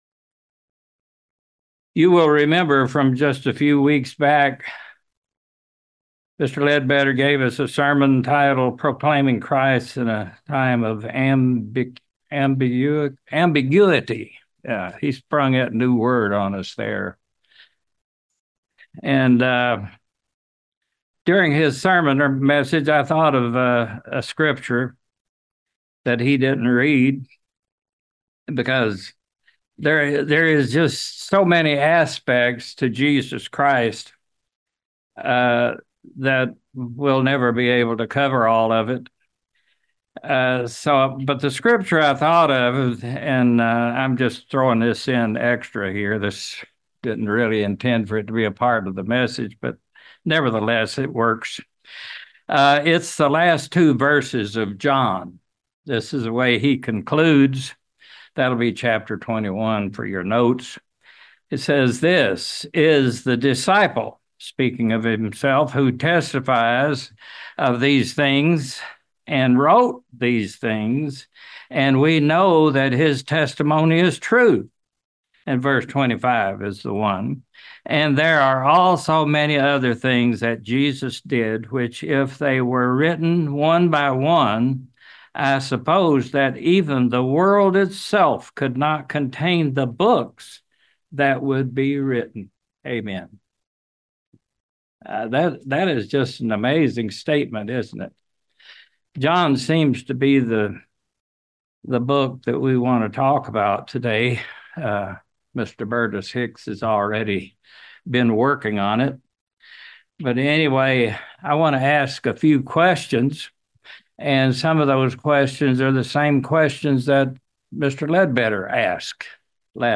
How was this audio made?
Given in London, KY